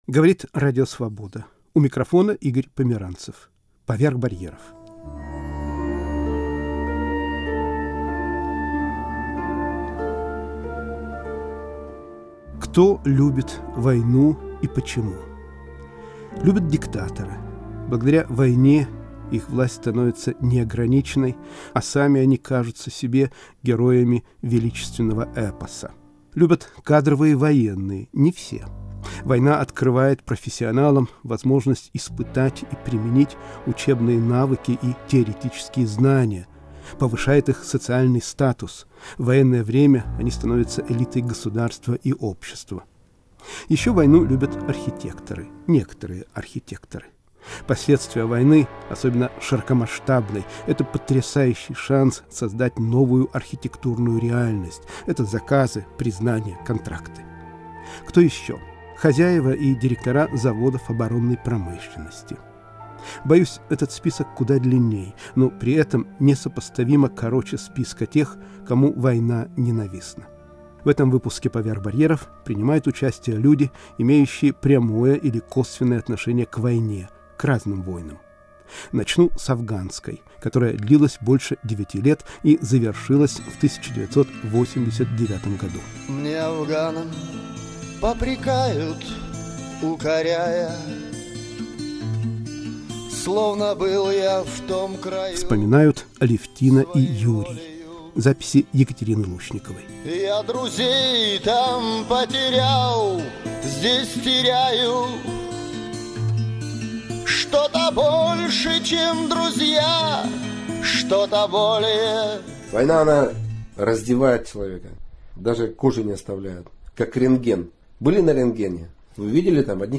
В передаче звучат голоса ветеранов Афганской войны, военкоров, беженцев из Абхазии, поэта-фронтовика